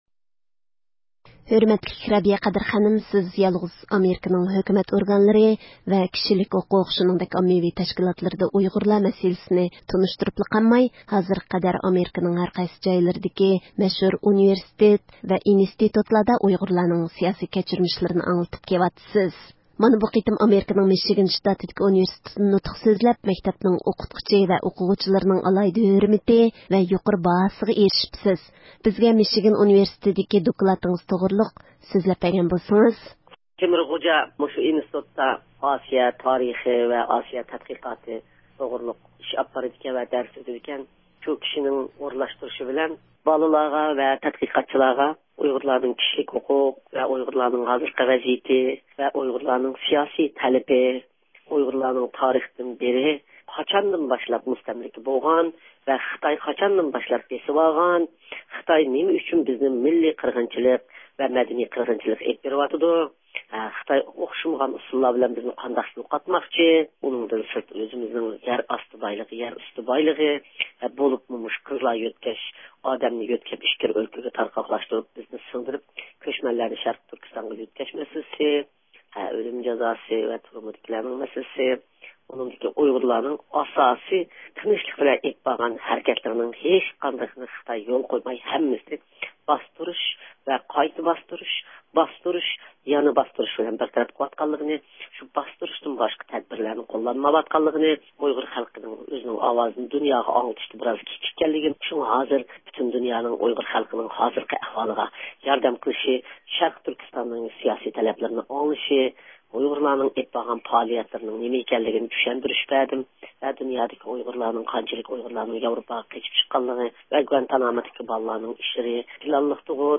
سۆھبىتىنى